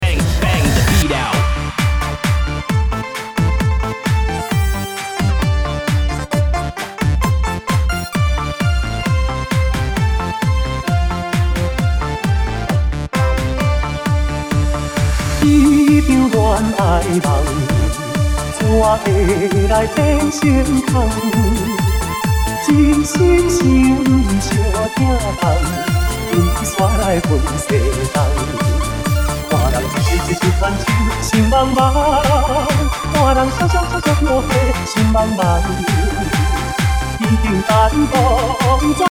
此曲為試聽版本！